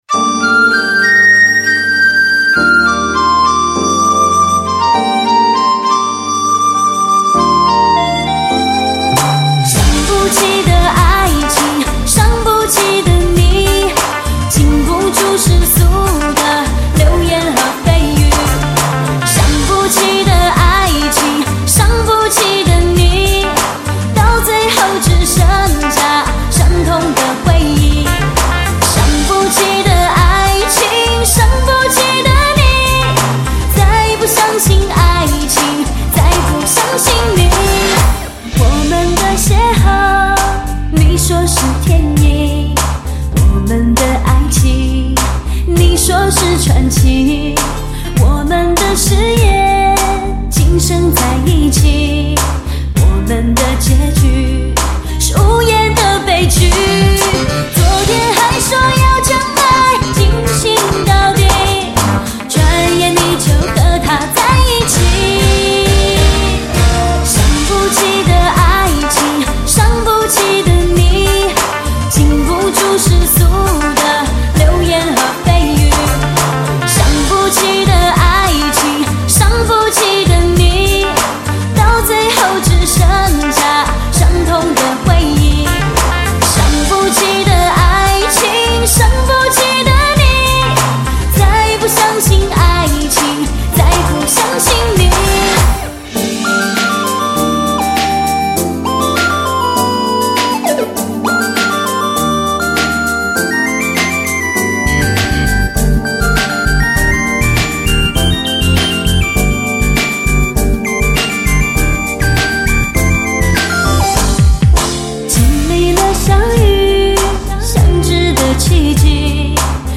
专辑格式：DTS-CD-5.1声道